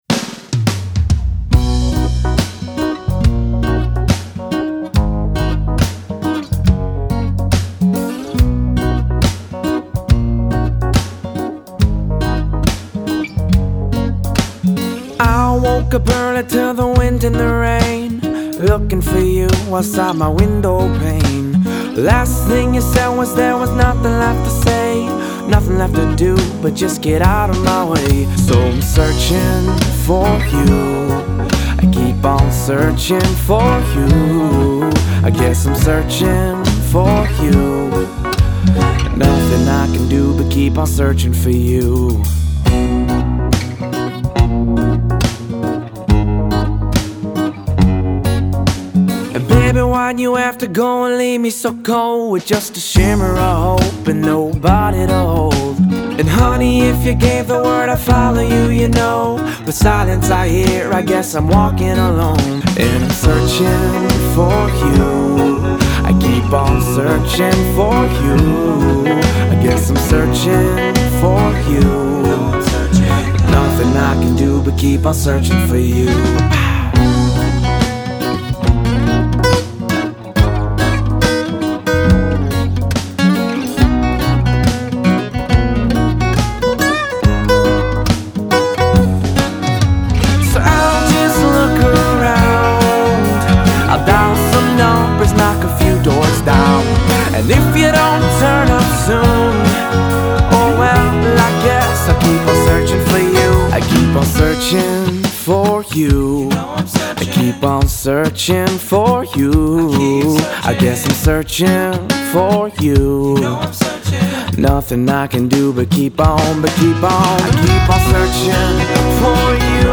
a light hearted pop tune.